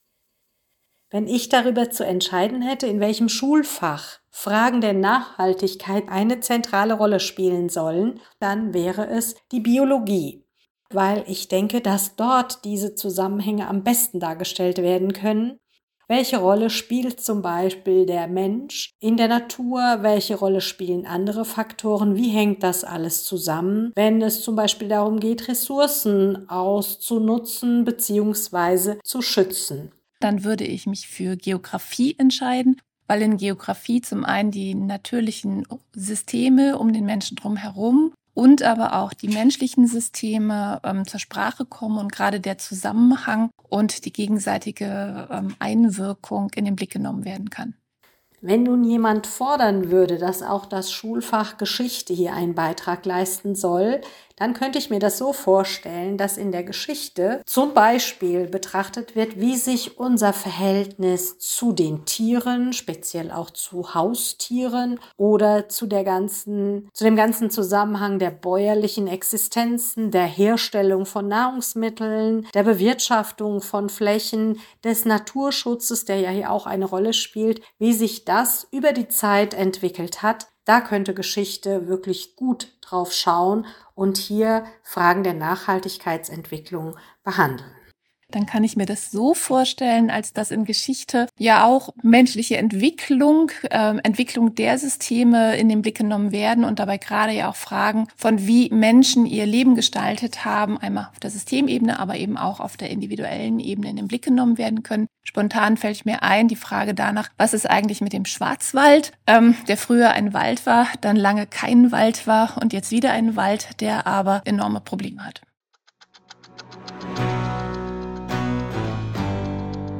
Wir sprechen über Nutzung von Ressourcen und Umgang mit Ressourcen. Genauer: Wir sprechen über Starkregenereignisse und verheerende Hochwasser im Spätmittelalter, über Wölfe als bedrohliches Stereotyp in der wissenschaftlichen Literatur der Frühen Neuzeit, Landgewinnung für die Landwirtschaft im sowjetischen Zentralasien und umweltpolitische Kontroversen rund um den Baikalsee, den tiefsten und wasserreichsten See der Erde. Und wir unterhalten uns an diesen Beispielen auch über das geschichtswissenschaftliche Studieren, das mit Überraschungen gepflastert sein kann.